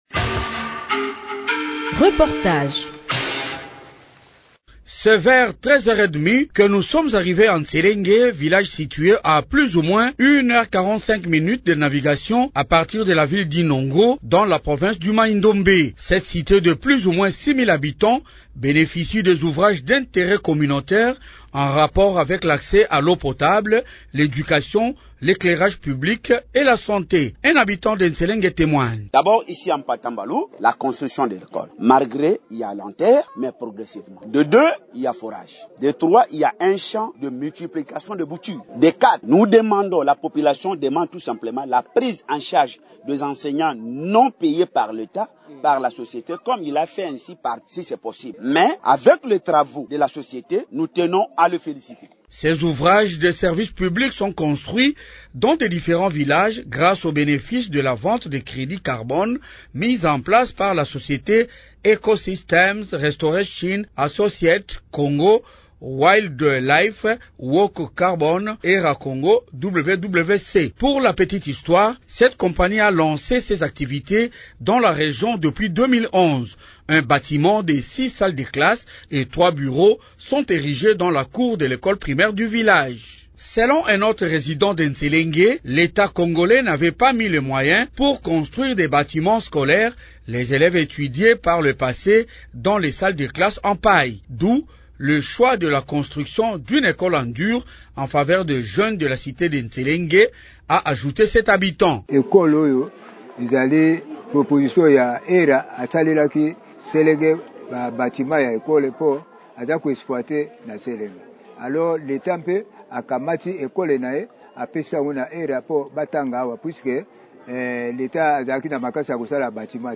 L’équipe d’Echo du développement vous invite à suivre ce grand reportage produit dans ces villages du 06 au 11 décembre 2022.